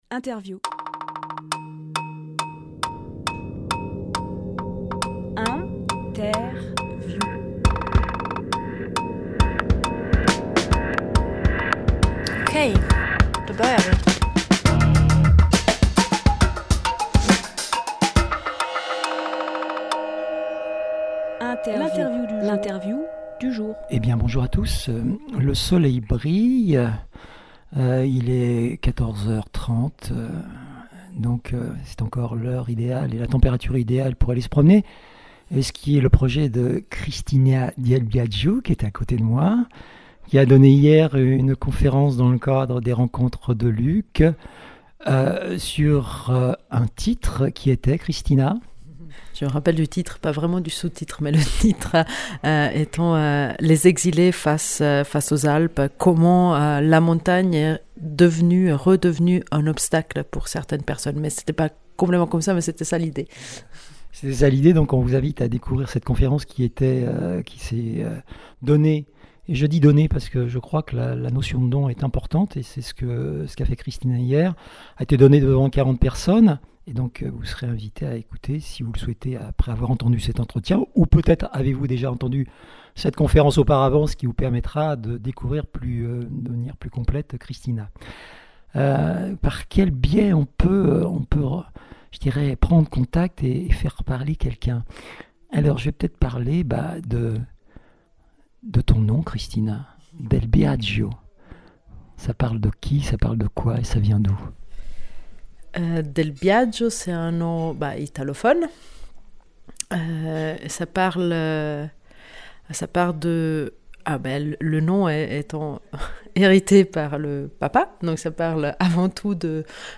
Emission - Interview